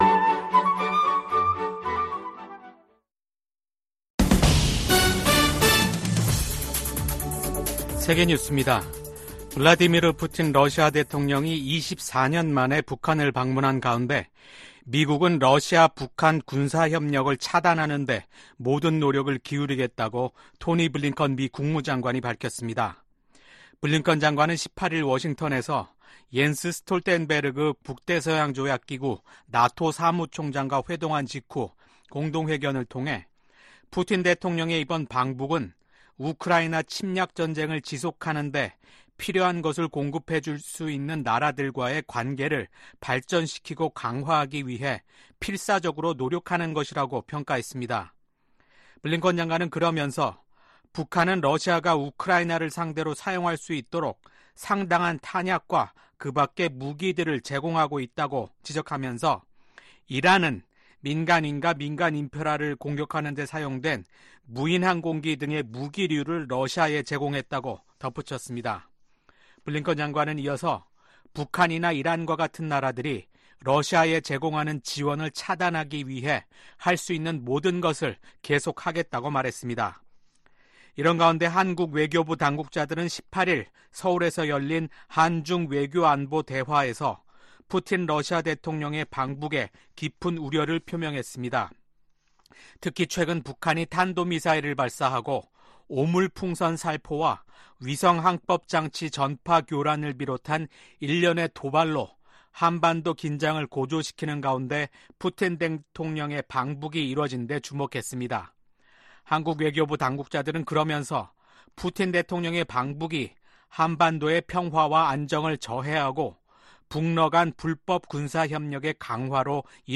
VOA 한국어 아침 뉴스 프로그램 '워싱턴 뉴스 광장' 2024년 6월 20일 방송입니다. 김정은 북한 국무위원장과 블라디미르 푸틴 러시아 대통령이 오늘, 19일 평양에서 정상회담을 갖고 포괄적 전략 동반자 협정에 서명했습니다. 미국 백악관은 푸틴 러시아 대통령이 김정은 북한 국무위원장에게 외교가 한반도 문제 해결의 유일한 해법이라는 메시지를 전달해야 한다고 촉구했습니다.